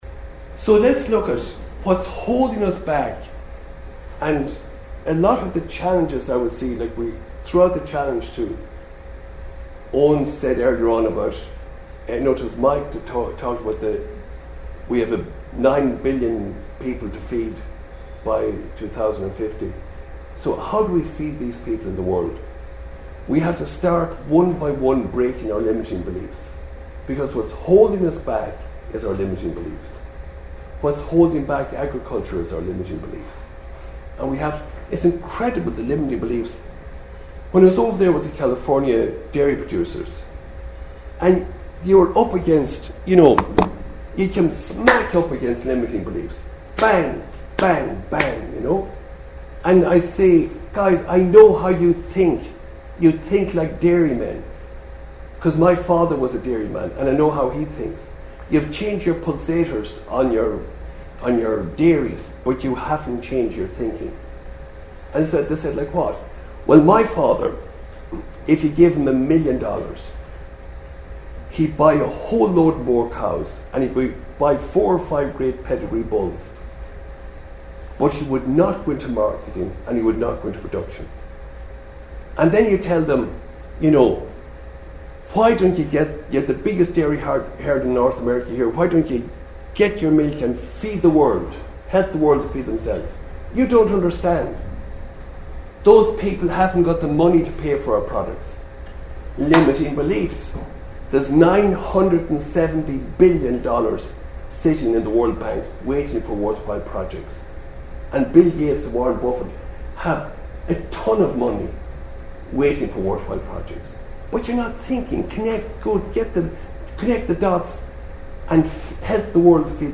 presentation